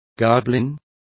Complete with pronunciation of the translation of goblins.